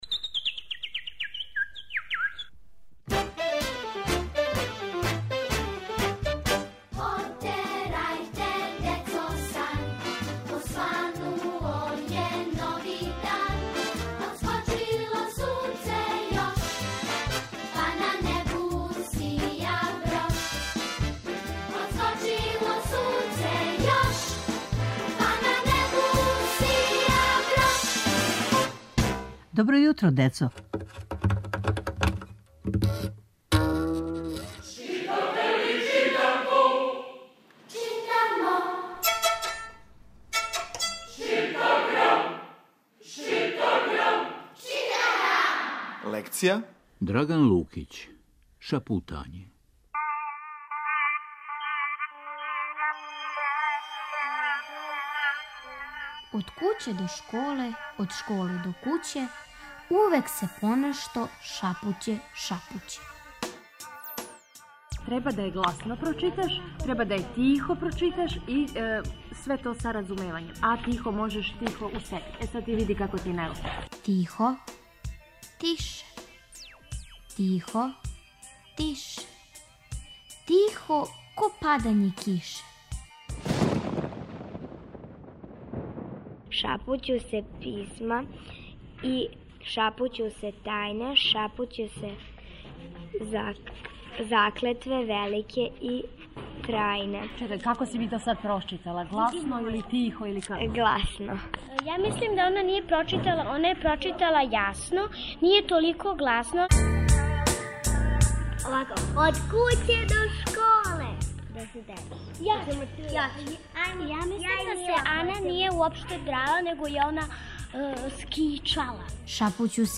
Сваког понедељка у емисији Добро јутро, децо - ЧИТАГРАМ: Читанка за слушање.